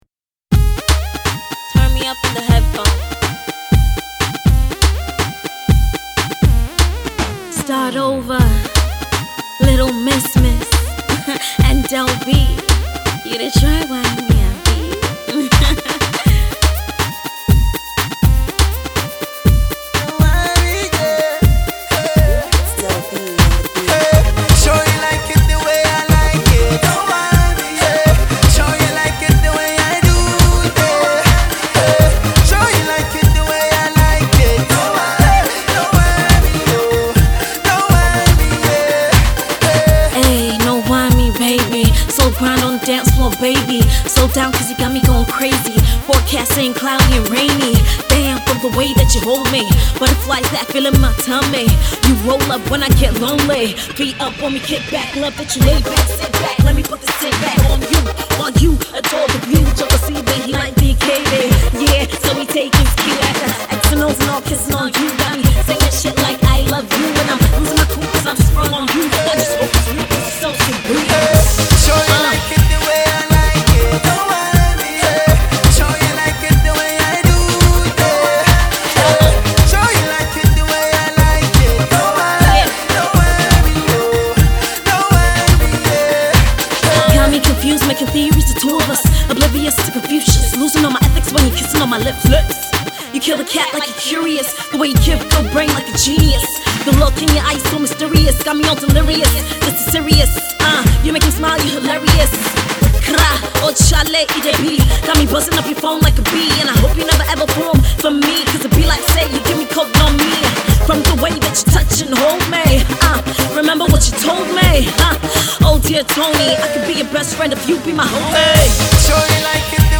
catchy chorus
exciting drums and synths
rap verses